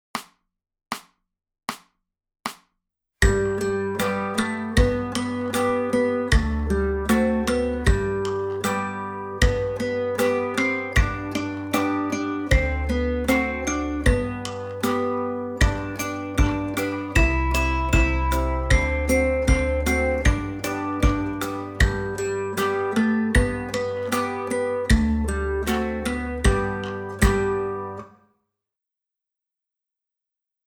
Melodie